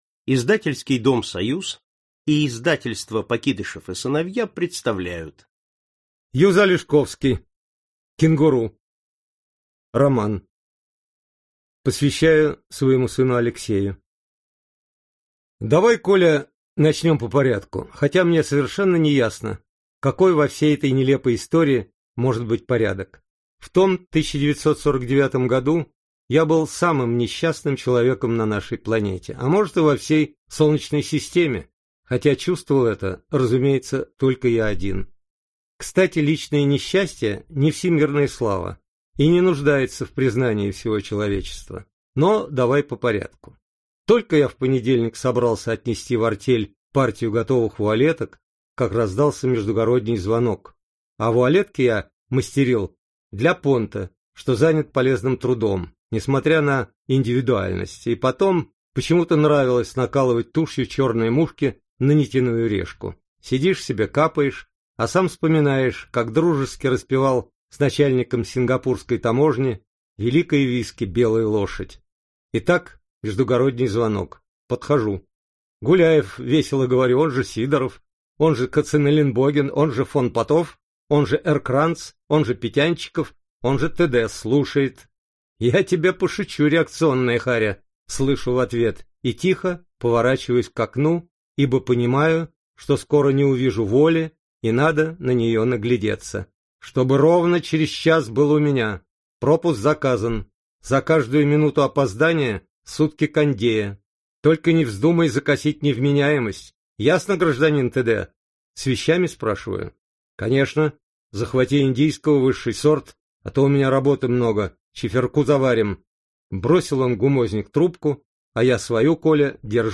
Аудиокнига Кенгуру | Библиотека аудиокниг
Aудиокнига Кенгуру Автор Юз Алешковский Читает аудиокнигу Юз Алешковский.